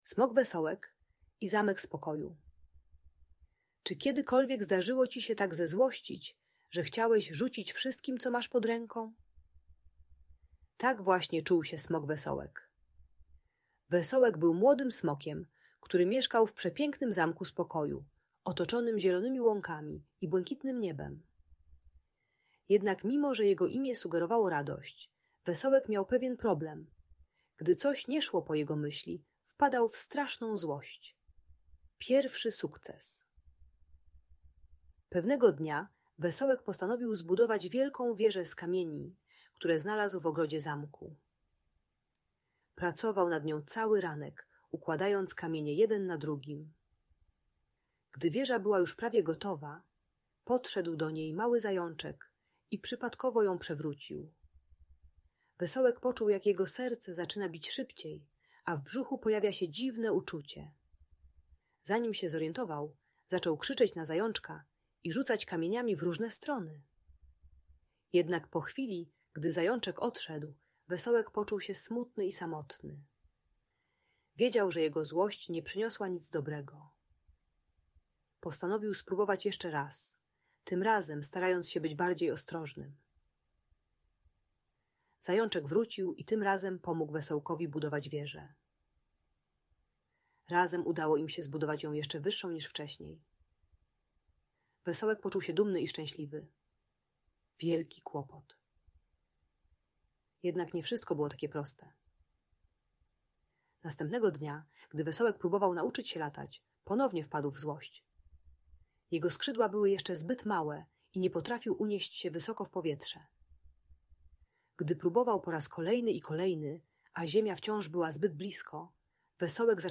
Smok Wesołek i Zamek Spokoju - story o emocjach - Audiobajka